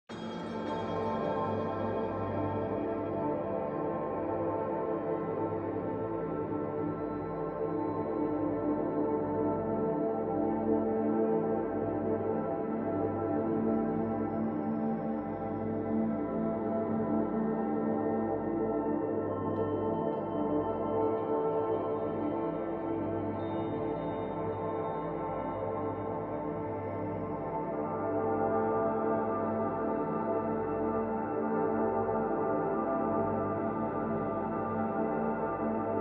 Binaural and Isochronic beats